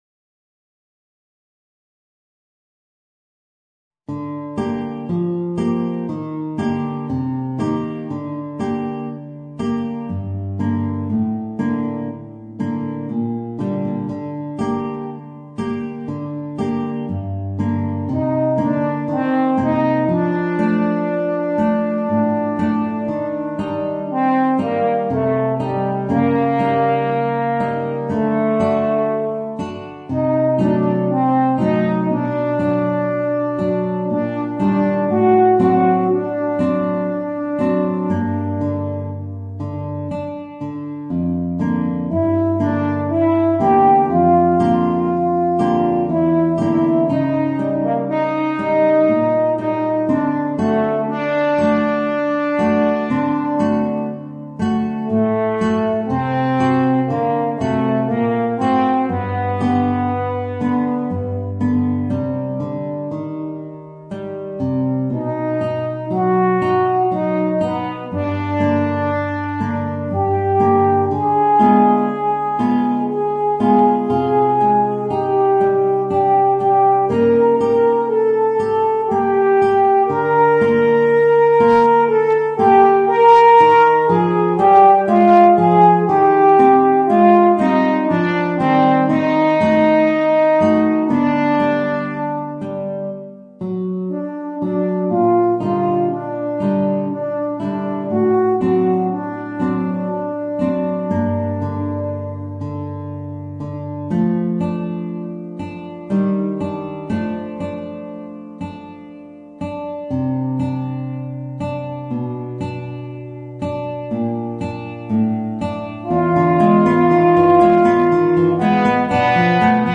Voicing: Eb Horn and Guitar